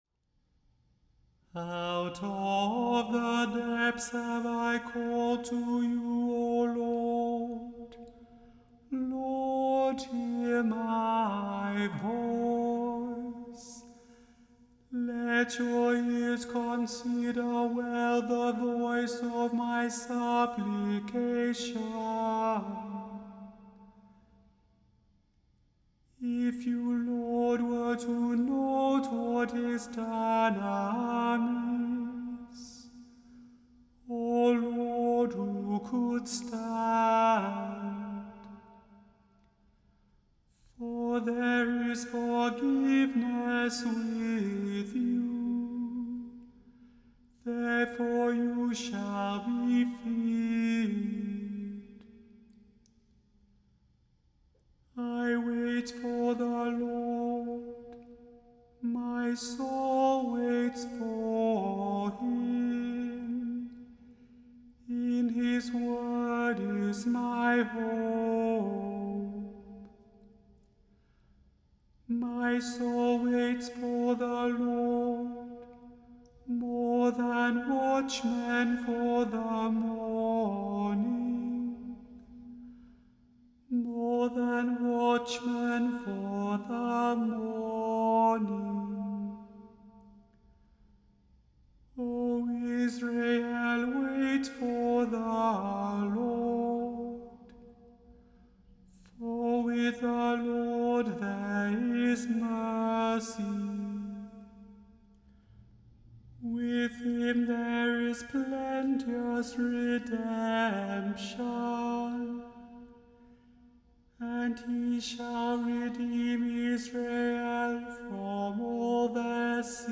The Chant Project – Chant for today (March 29) – Psalm 130